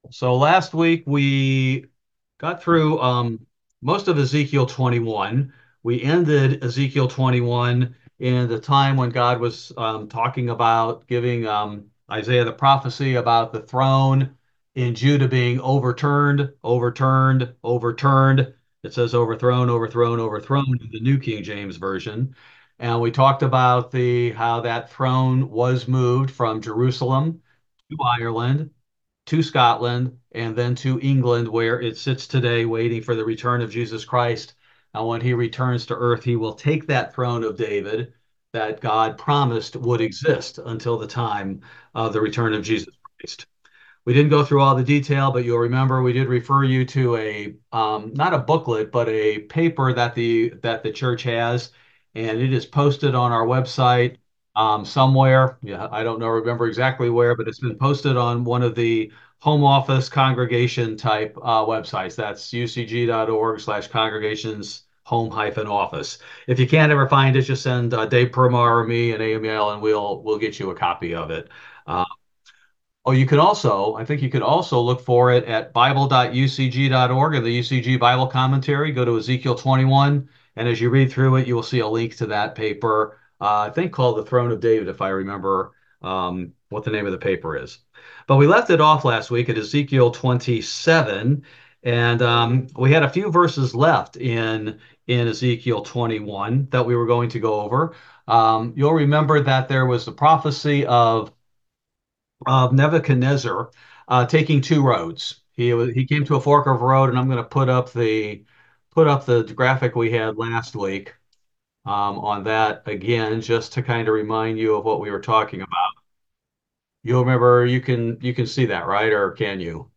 Bible Study: November 13, 2024